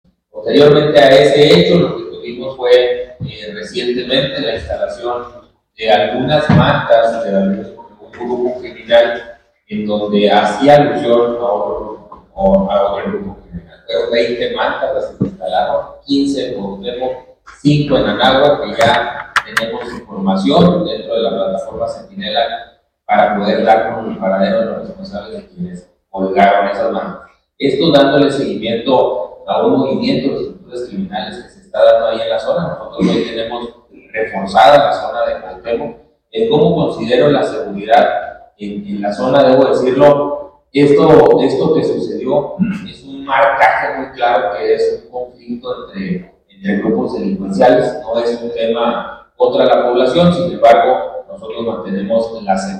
AUDIO: GILBERTO LOYA CHÁVEZ, SECRETARIO DE SEGURIDAD PÚBLICA DEL ESTADO (SSPE)